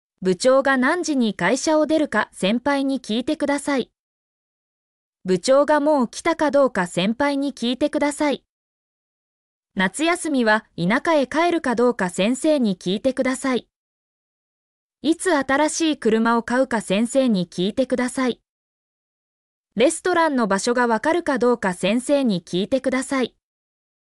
mp3-output-ttsfreedotcom-25_ob29TRCy.mp3